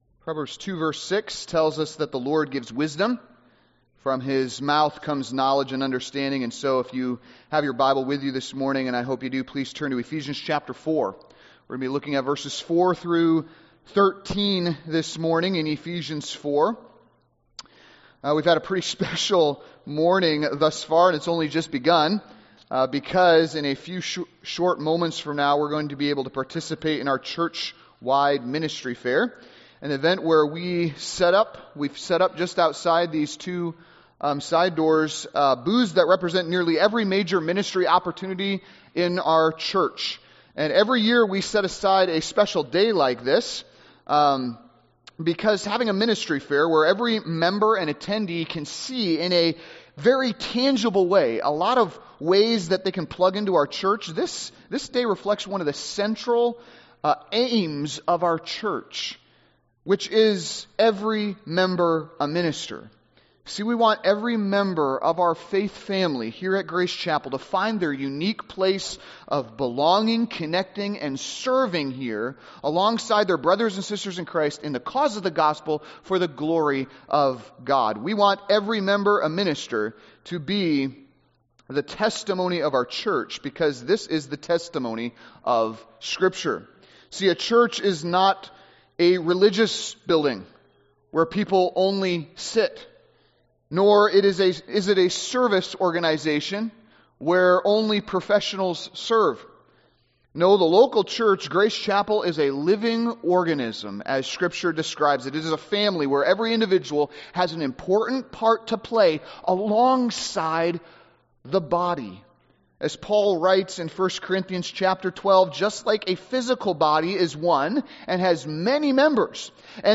Sermons | Grace Chapel